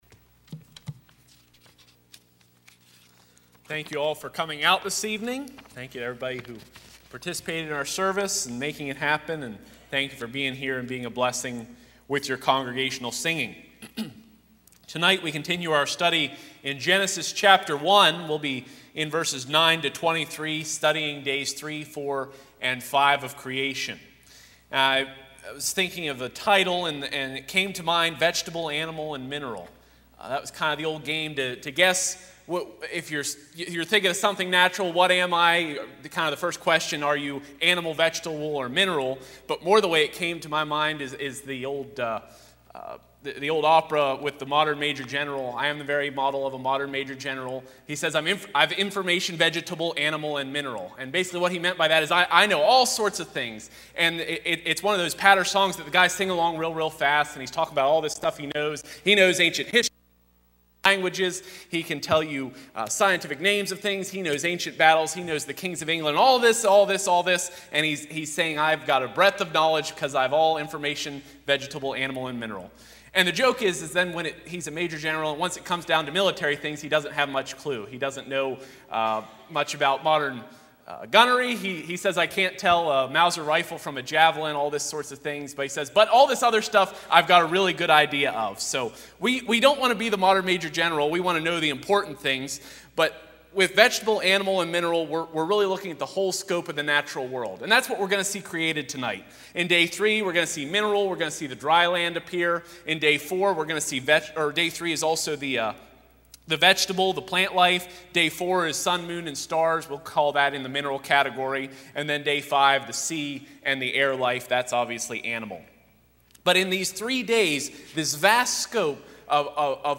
Passage: Genesis 1:9-23 Service Type: Sunday 6:00PM I. The Land II.